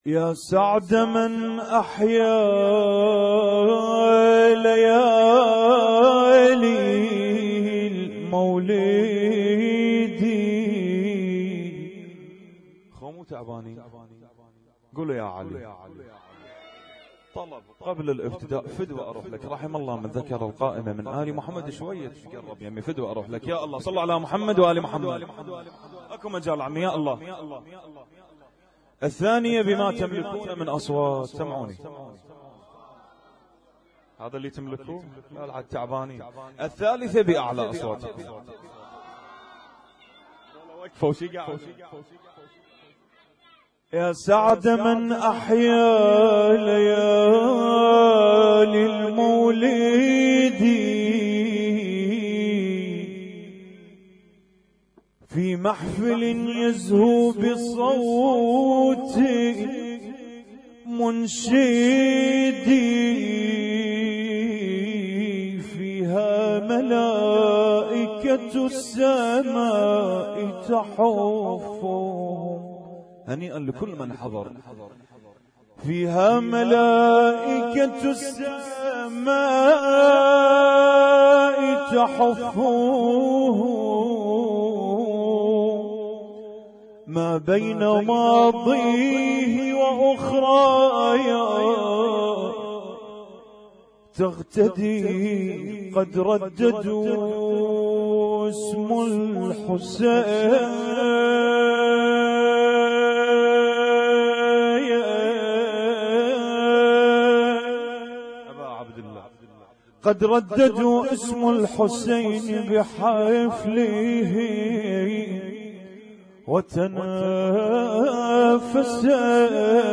اسم التصنيف: المـكتبة الصــوتيه >> المواليد >> المواليد 1438